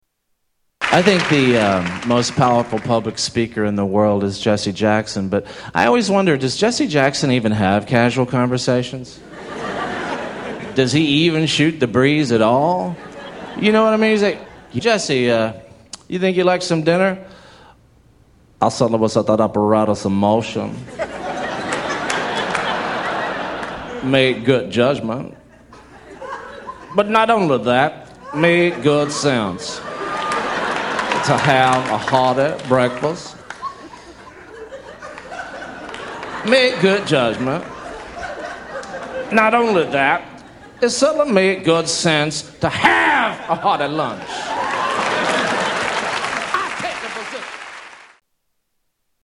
Category: Comedians   Right: Personal
Tags: Comedians Darrell Hammond Darrell Hammond Impressions SNL Television